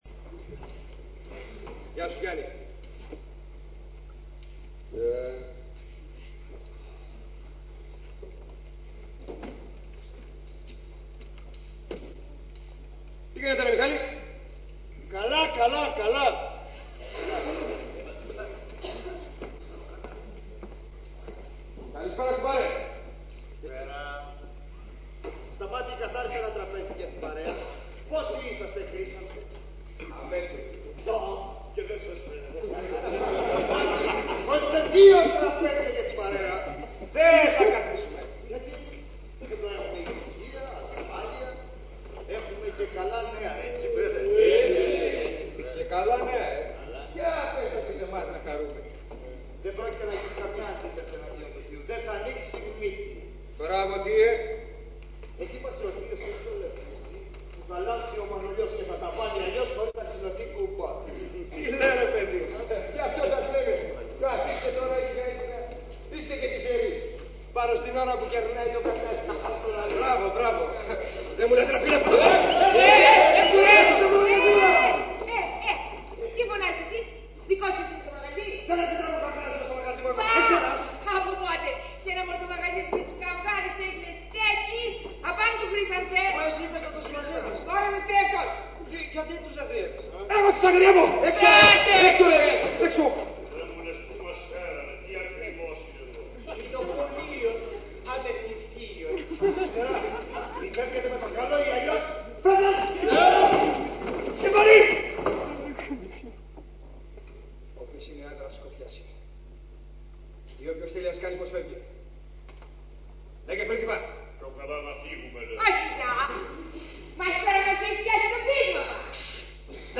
Ηχογράφηση Παράστασης
Αποσπάσματα από την ηχογράφηση της παράστασης